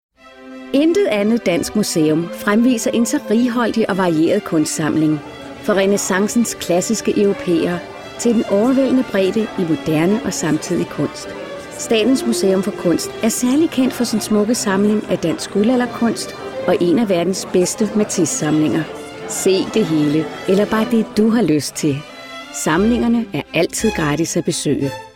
intelligent, varm, sofistikeret, og klar.
Sprechprobe: Werbung (Muttersprache):
My voice is warm, sincere, sophisticated and intelligent.